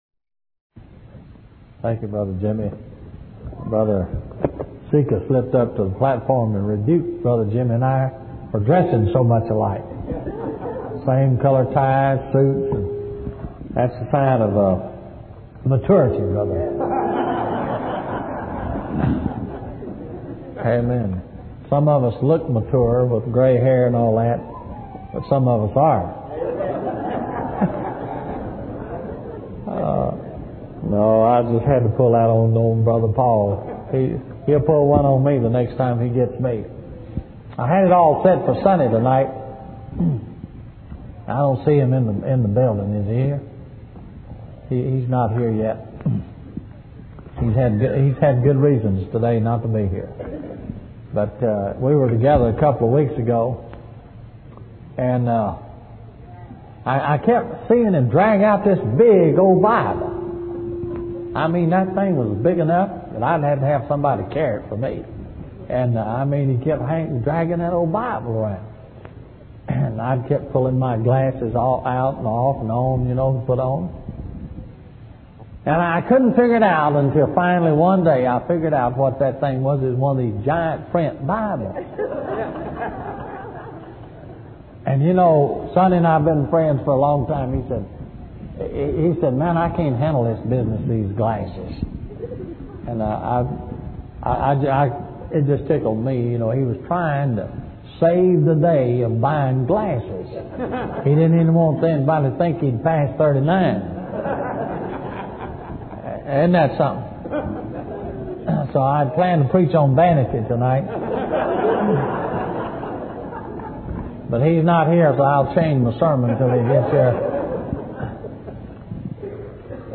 In this sermon, the preacher reflects on his experiences with preaching the Word of God and witnessing people's reactions to it. He shares how he observed people getting offended by the Word of God and falling away, which deeply shook him.